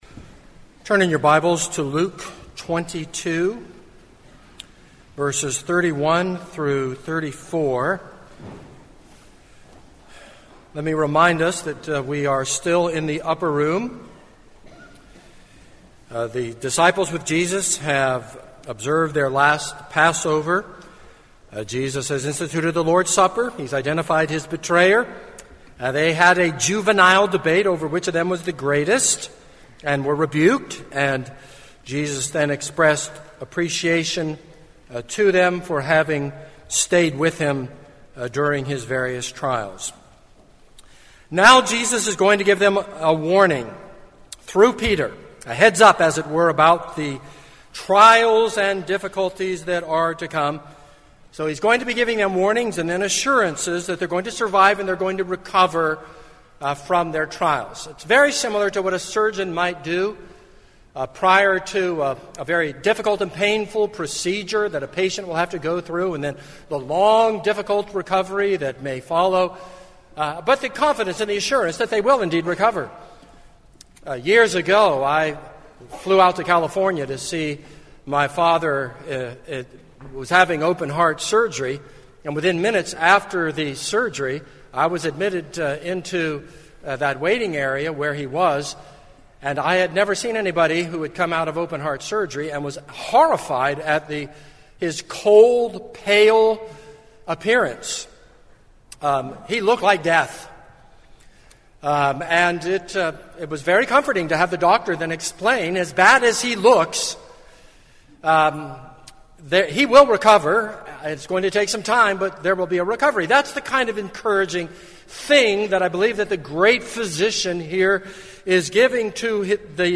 This is a sermon on Luke 22:31-34.